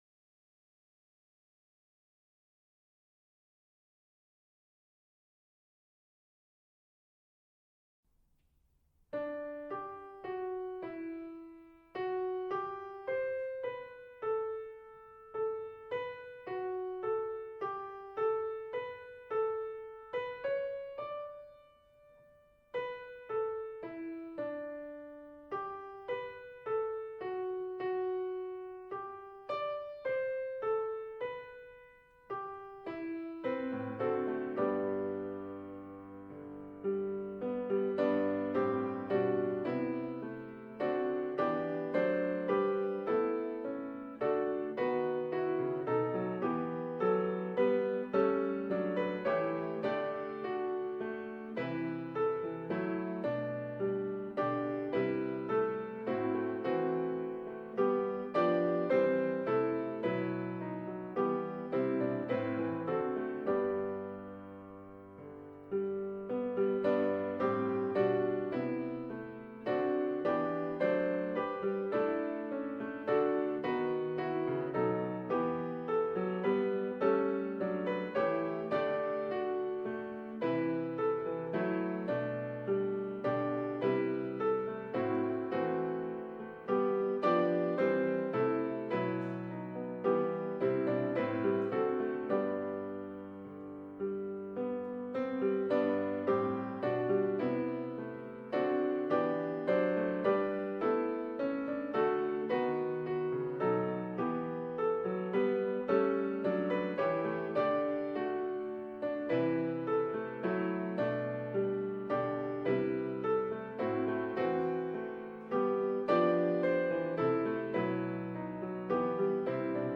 HYMN: Alan Luff ©